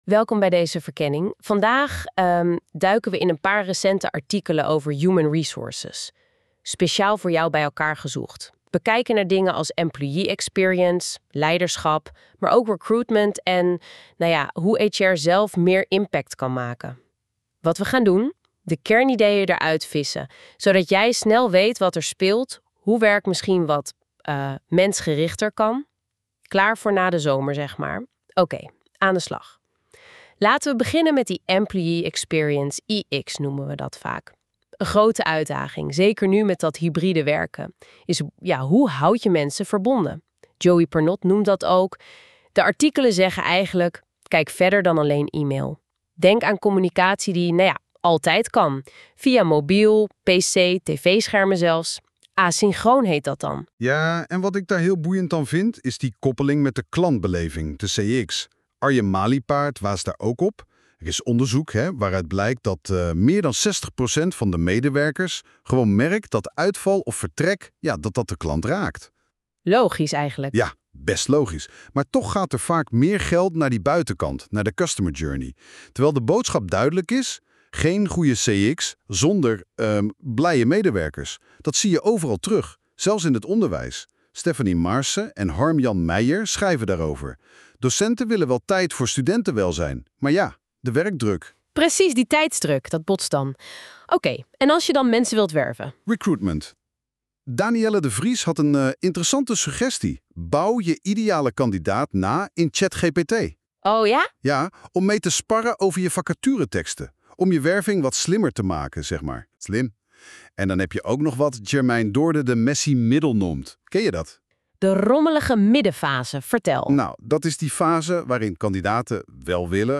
Luister je liever dan je leest? Laat je door 2 AI-hosts in ruim 5 minuten bijpraten over deze summerread, gegenereerd door NotebookLM.